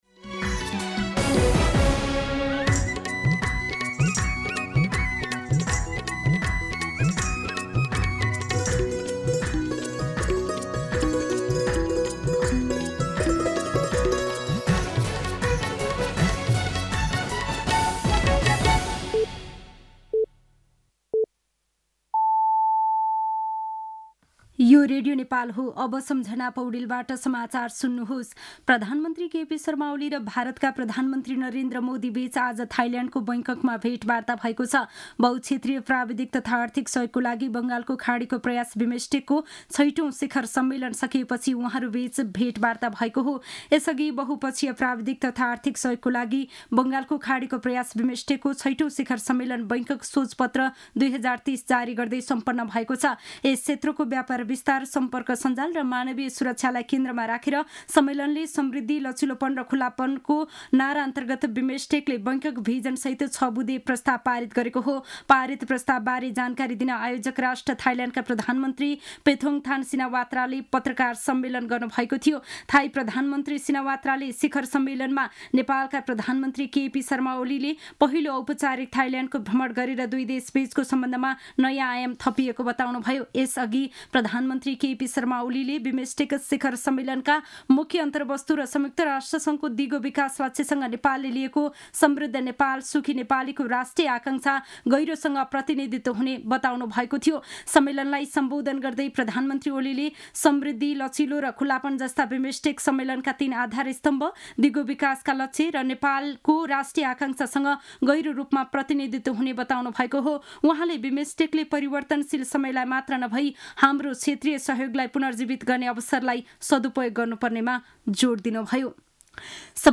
दिउँसो ४ बजेको नेपाली समाचार : २२ चैत , २०८१
4-pm-Nepali-News-1.mp3